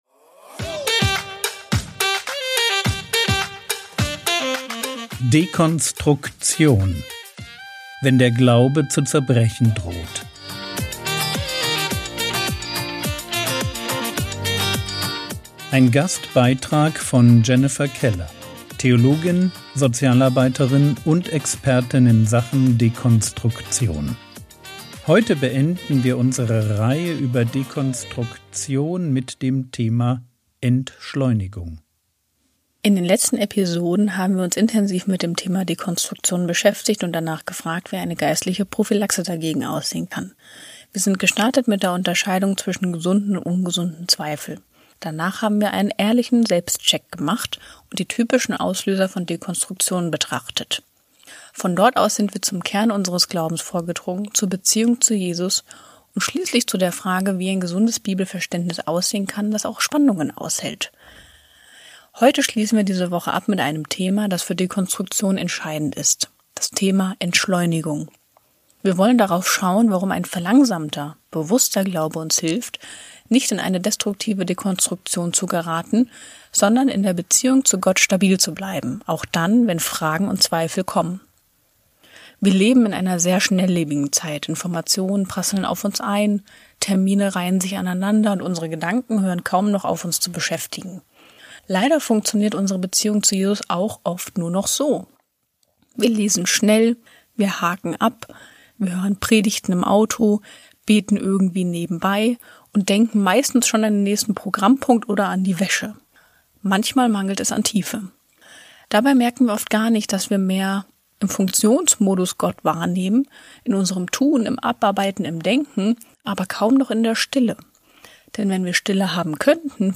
Dekonstruktion - Wenn der Glaube zu zerbrechen droht... ~ Frogwords Mini-Predigt Podcast